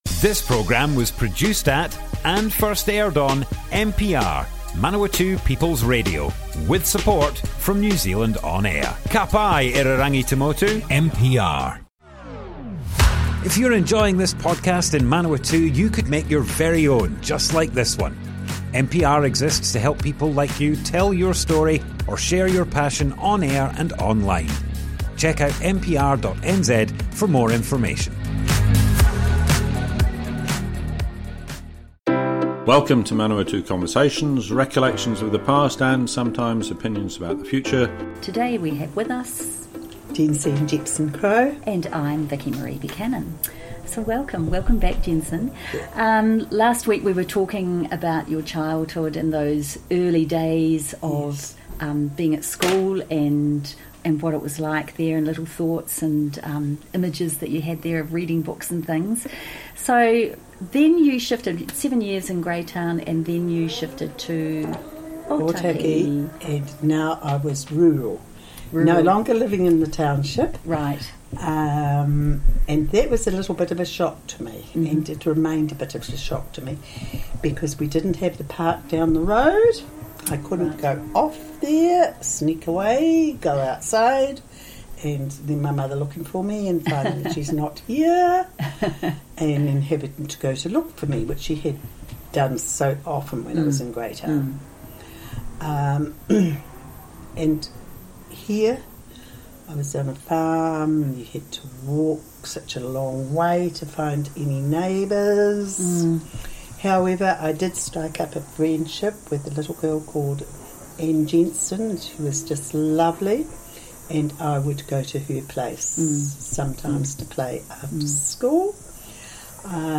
Manawatu Conversations More Info → Description Broadcast on Manawatu People's Radio, 28th February 2023.
oral history